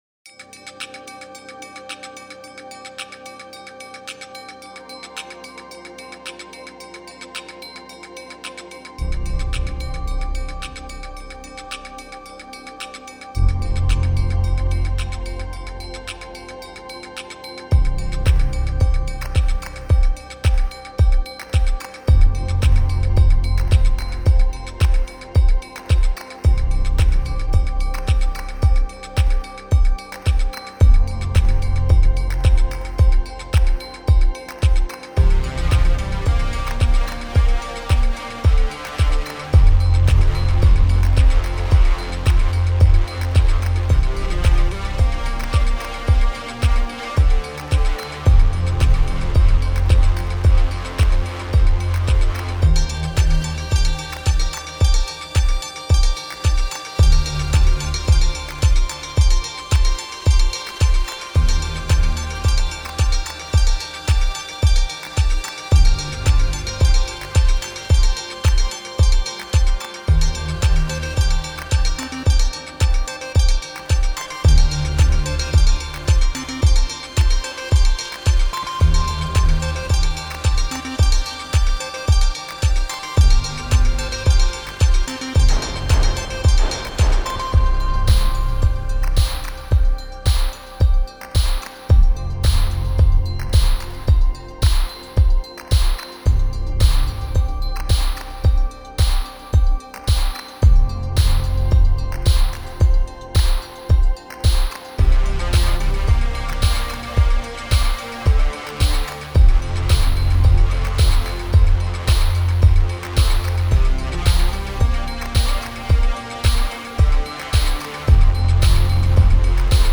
Powering, crackling, clanging, glowing warm in the deep